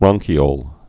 (brŏngkē-ōl)